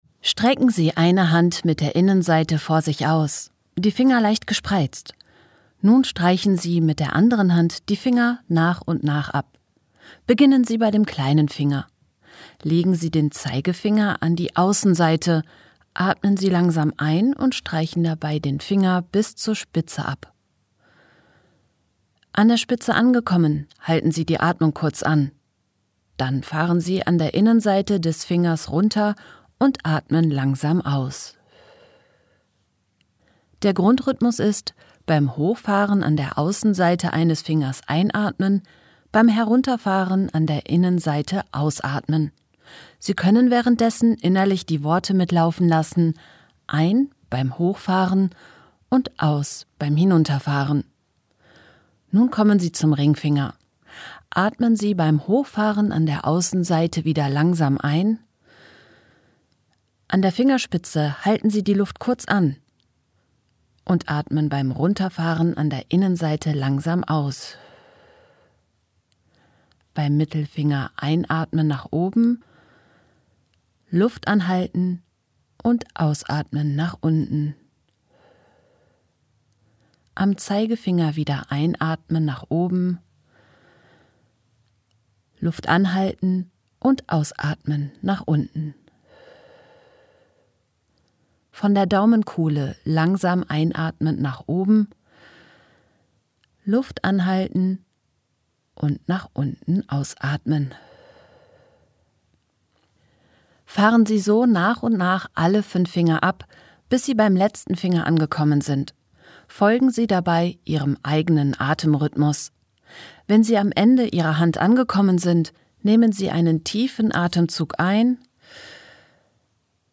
Mit unserem 90-Sekunden-Reset bieten wir eine kurze Audioanleitung für die sogenannte Fünf-Finger-Atmung – eine einfache Atemtechnik, die sich direkt in den Pflegealltag integrieren lässt.
5_Finger_Atmung_2026.mp3